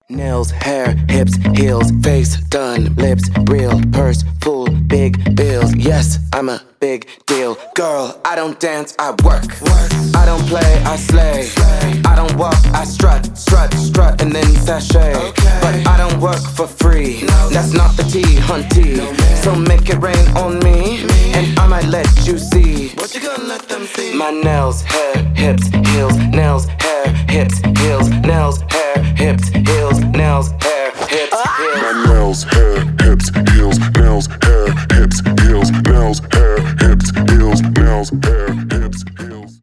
танцевальные
house